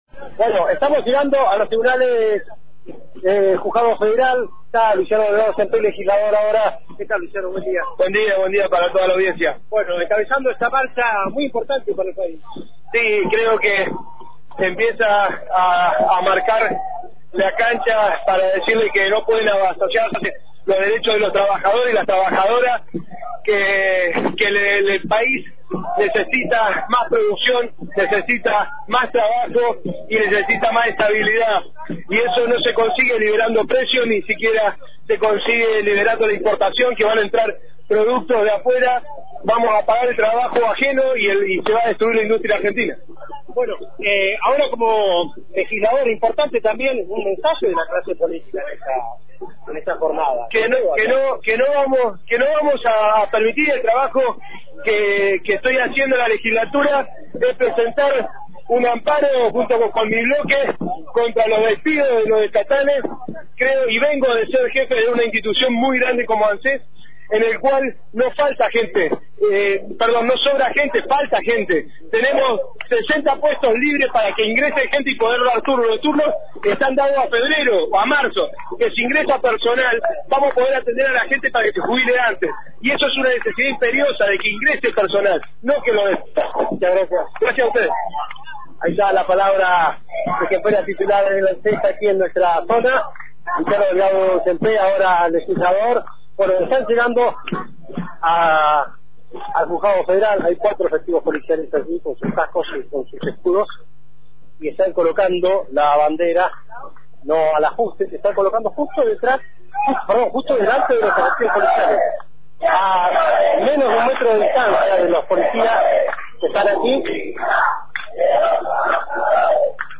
Antena Libre realizó una cobertura especial desde la movilización